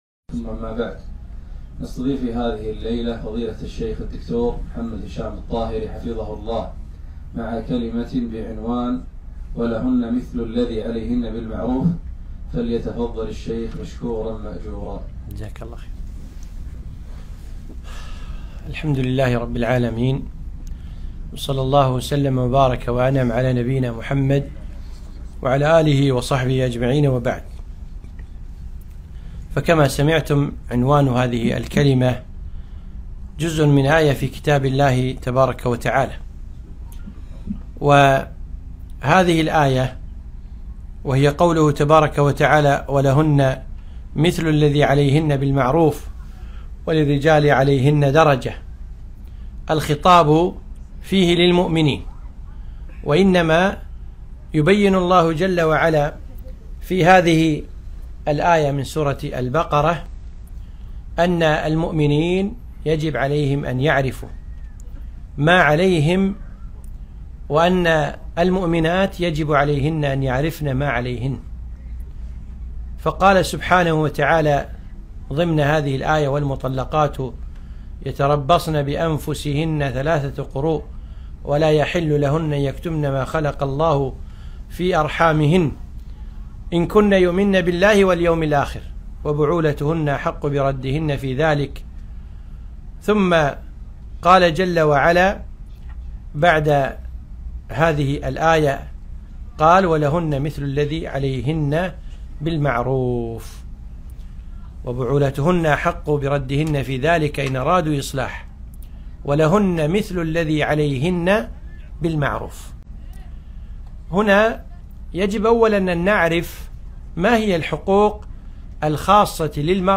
محاضرة - (( ولهن مثل الذي عليهن بالمعروف ))